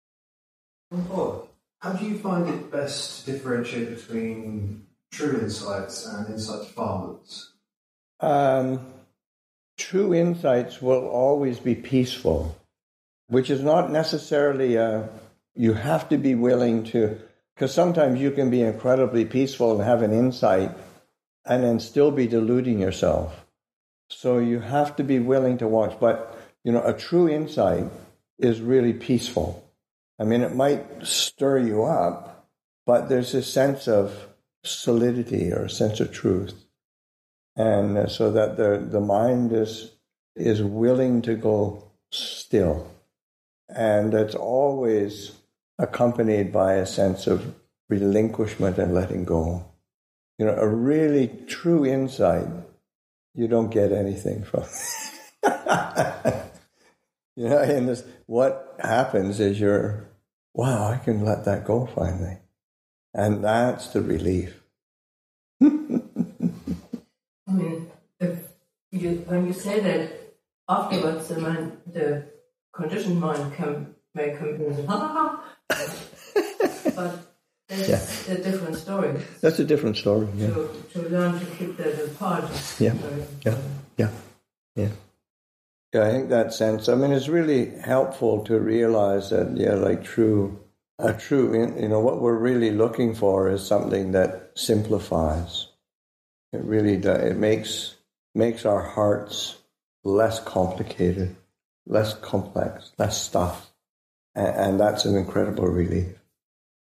Q&A with the Chithurst Community [2025], Excerpt 6